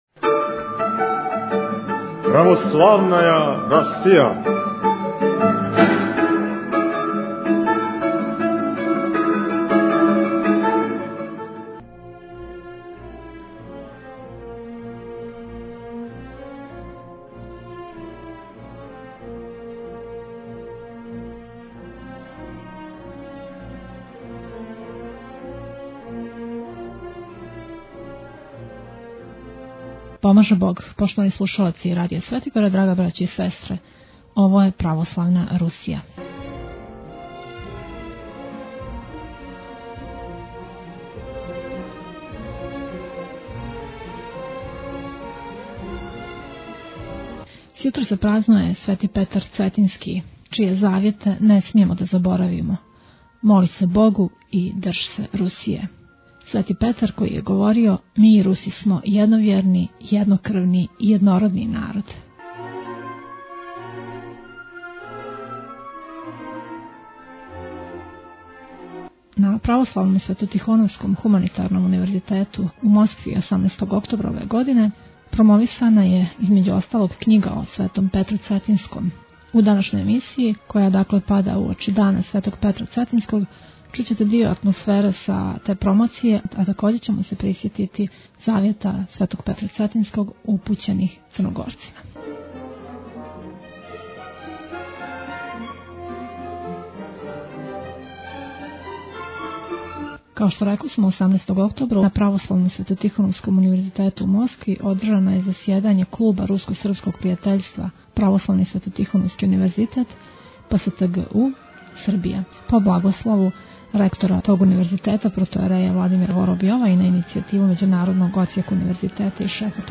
такође ћемо пренијети дио атмосфере са вечери одржане на Православном светотихоновском универзитету у Москви (ПСТГУ)